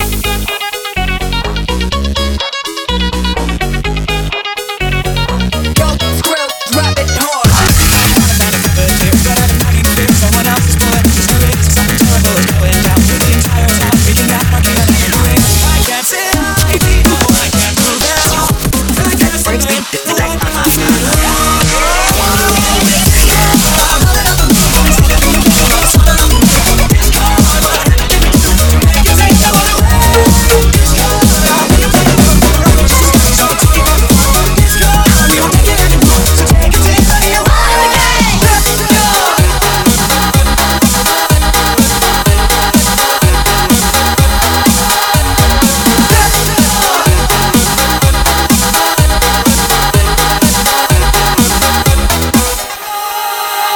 It's a pisscore remix of brony music.